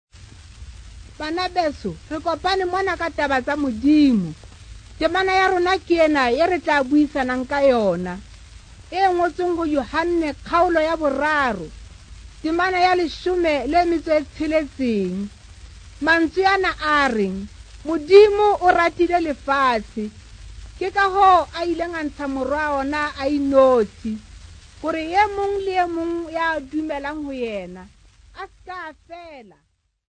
Dutch Reformed Church Choir
Folk Music
Field recordings
Africa South Africa Johannesburg f-sa
sound recording-musical
Indigenous music
Vestax BDT-2500 belt drive turntable